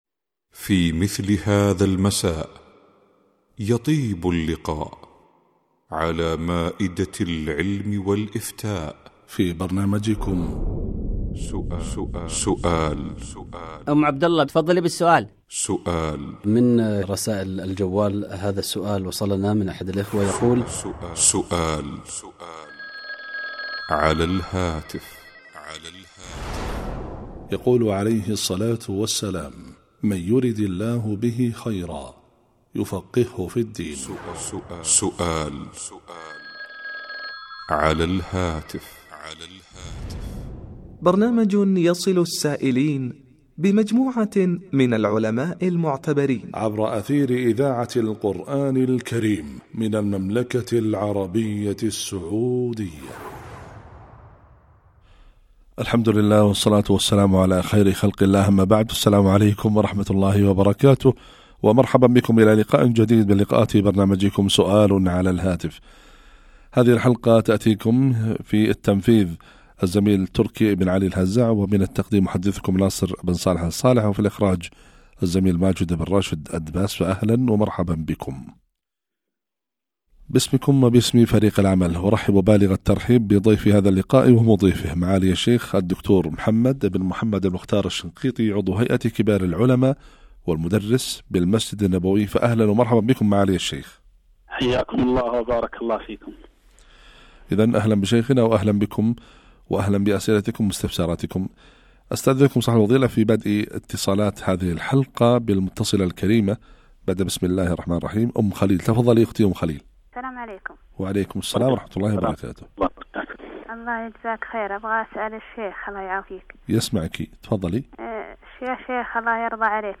الفتاوى سؤال على الهاتف